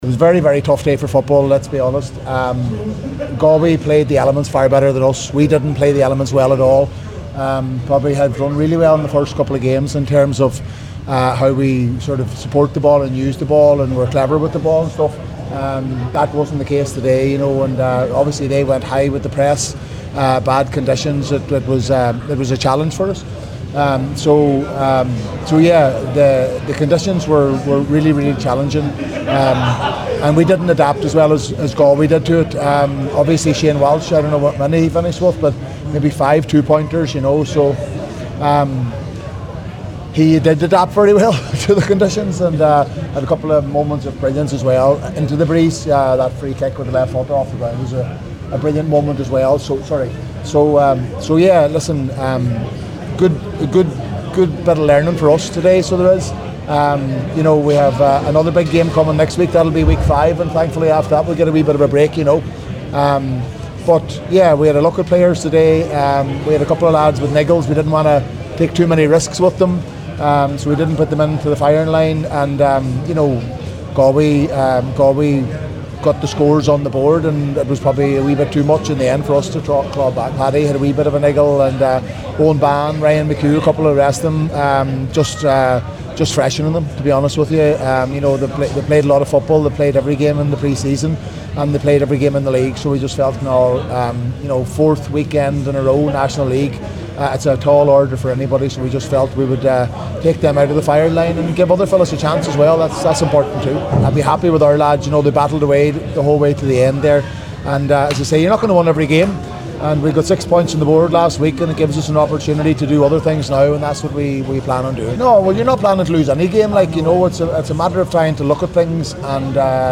Donegal manager Jim McGuinness
After the game, McGuinness spoke to the assembled media and said it was “a very very tough day for football”…